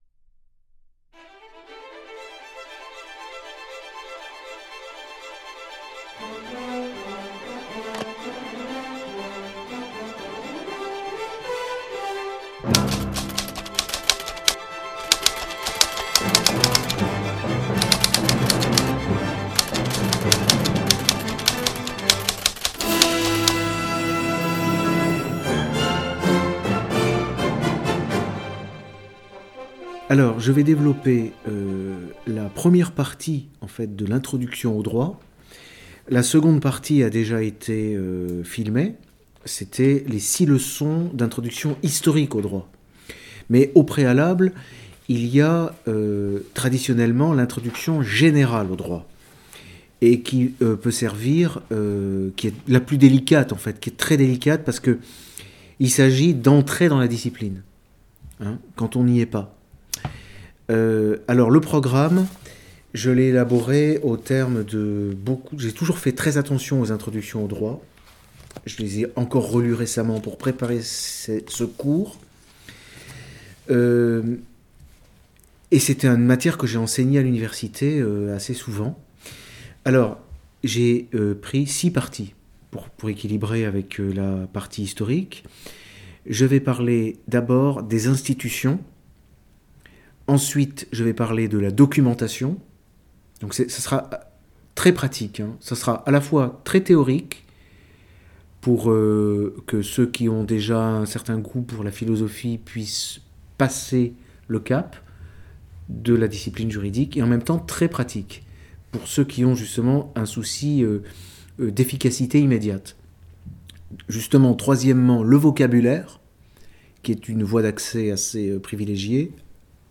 Les cahiers de vacances d'ERFM – Leçons d'initiation au droit